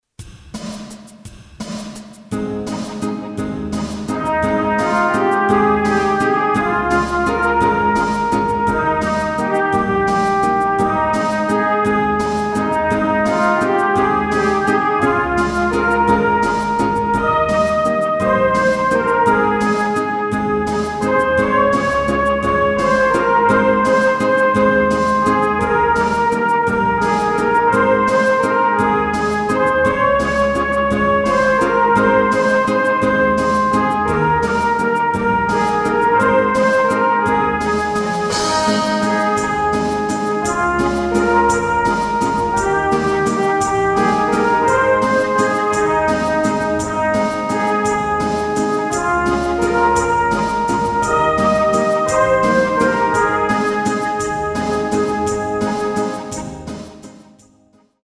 Flauta Música: MIDI 1.